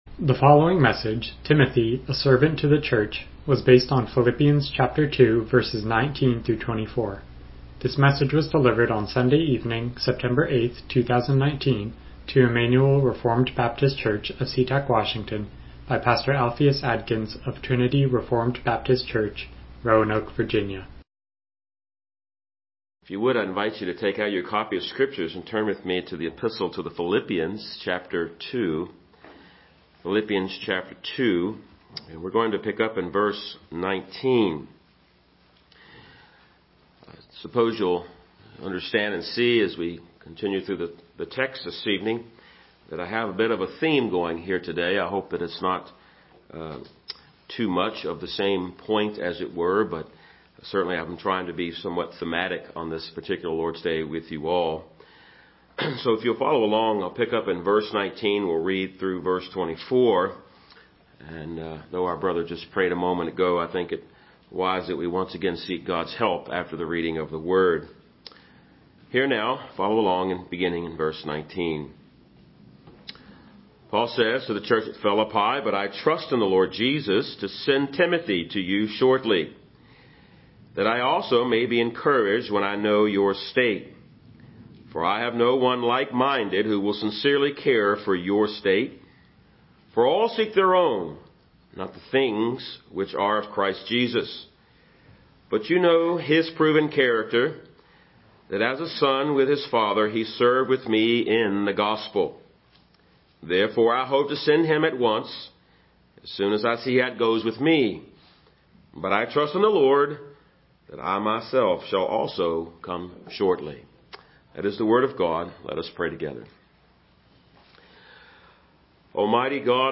Passage: Philippians 2:19-24 Service Type: Evening Worship